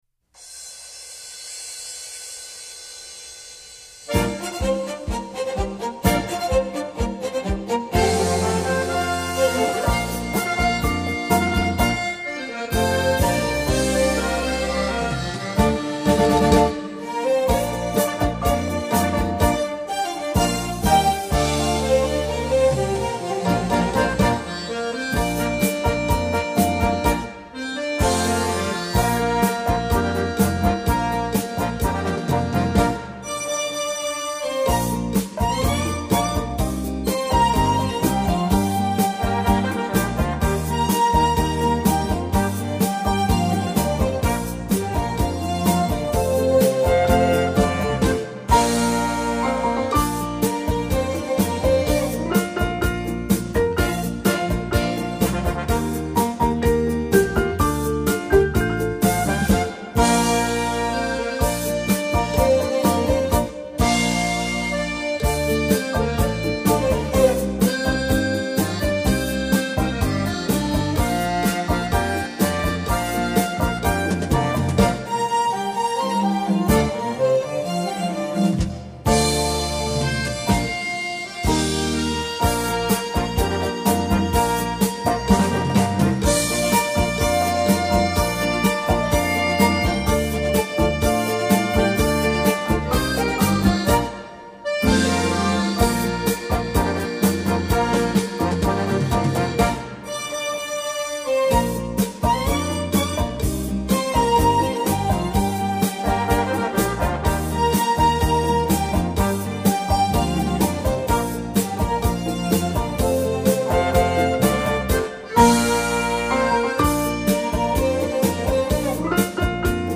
闲适优雅的音符，完美传真的音质，呈献在您的耳畔。
HI-FI级水准，余音绕梁的演奏，细细聆听家庭舞曲世界，
优美动听的舞蹈旋律，让您沉醉于翩翩起舞之中。